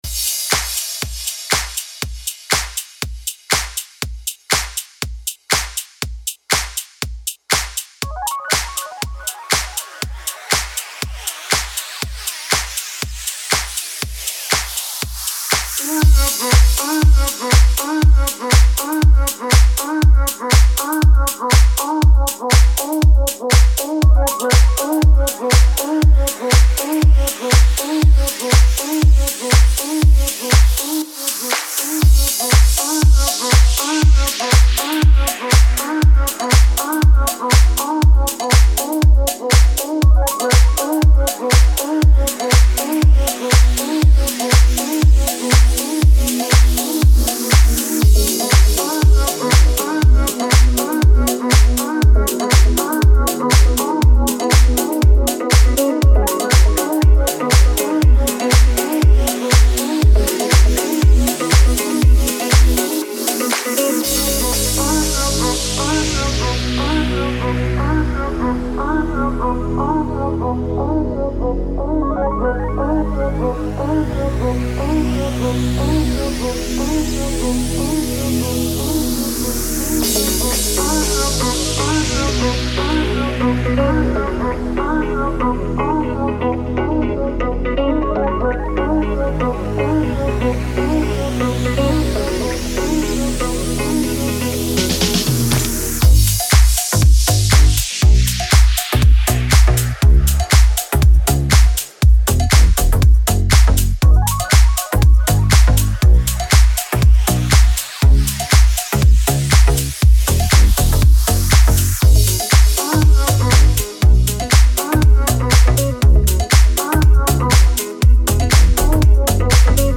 это трек в жанре прогрессив-хаус